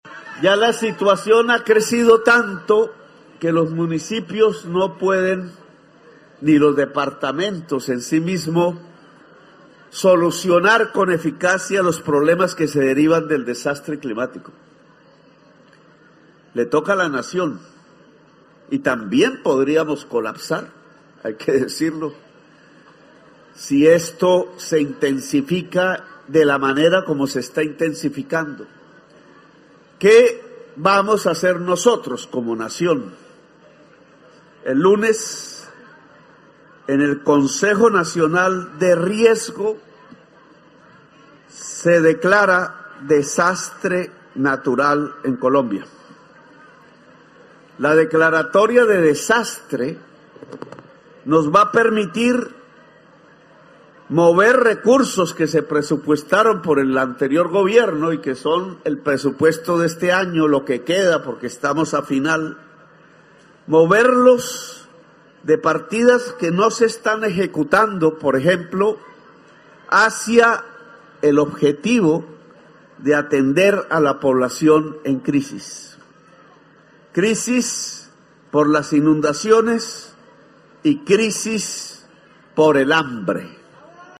Así lo anunció el Jefe de Estado en el municipio de Bosconia (Cesar), donde se instaló el Puesto de Mando Unificado (PMU) para enfrentar las emergencias sufridas en la región y donde el Jefe de Estado escuchó a la comunidad.